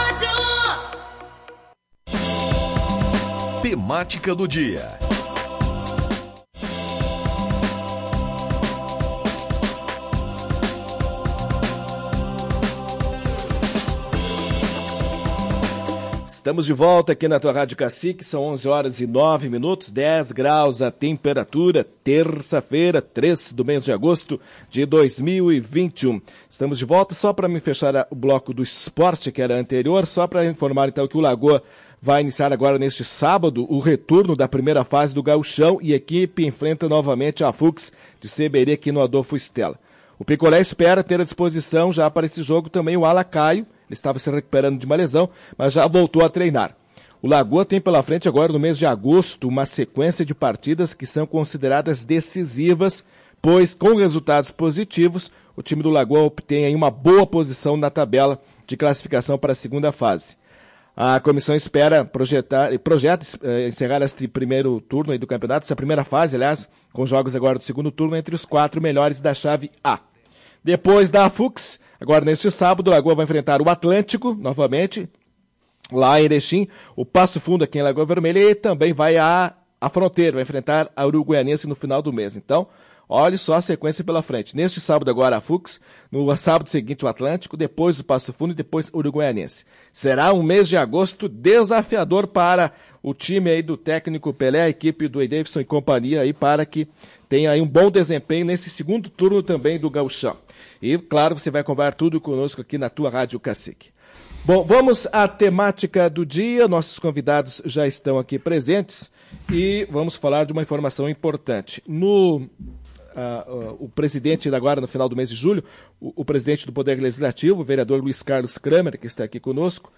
Na manhã desta terça-feira, 03 de agosto, o presidente do Legislativo e o vereador Gabriel Vieira (PP) falaram sobre o repasse e o retorno das Sessões da Câmara no segundo semestre de 2021.